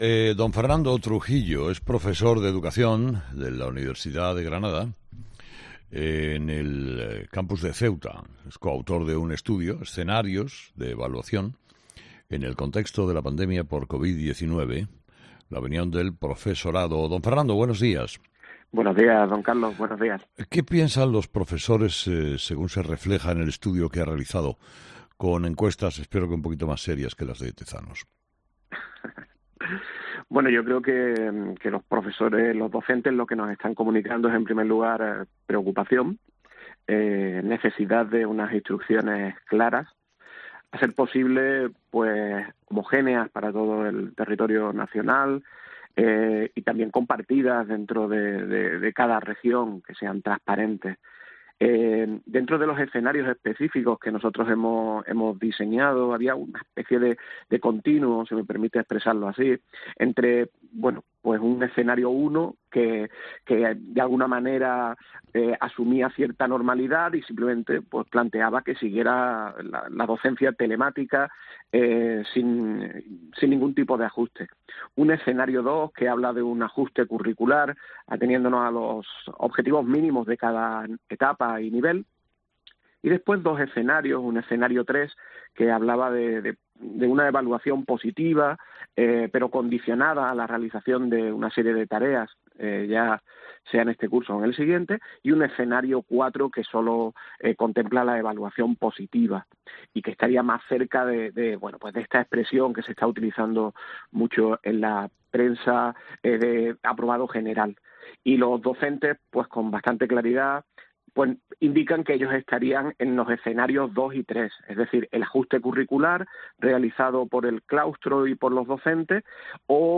En una entrevista en "Herrera en COPE" este jueves